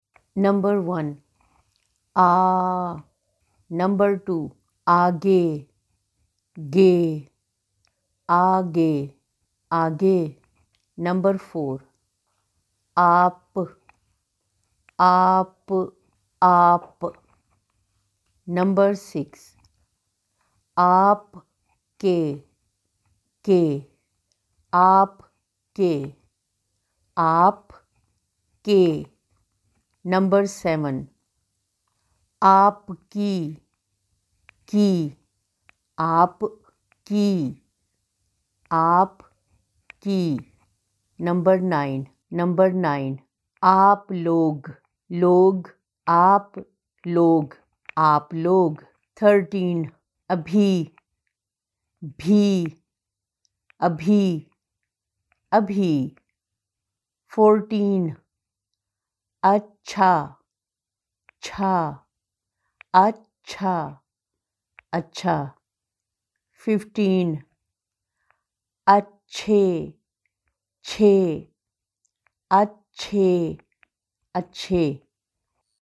Female
Adult
documentary